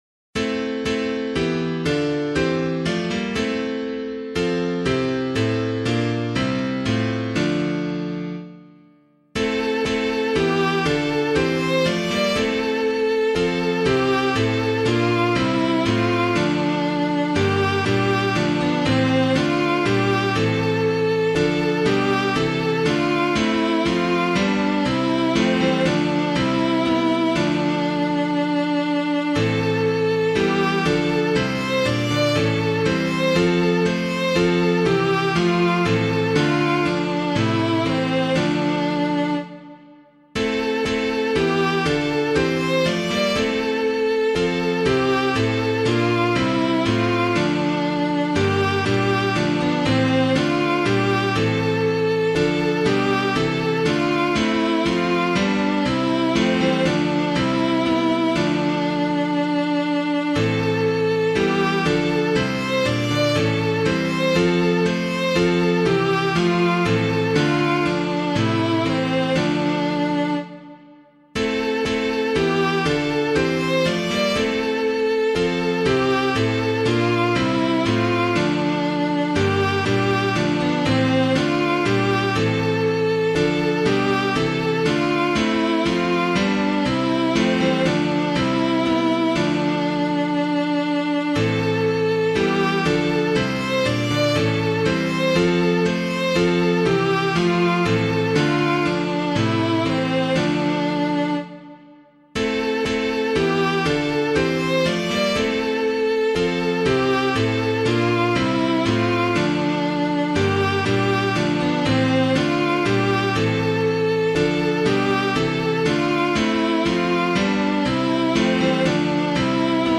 piano
Christ the Lord Is Risen Again [Winkworth - CHRIST IST ERSTANDEN] - piano.mp3